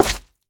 Minecraft Version Minecraft Version snapshot Latest Release | Latest Snapshot snapshot / assets / minecraft / sounds / block / froglight / break4.ogg Compare With Compare With Latest Release | Latest Snapshot
break4.ogg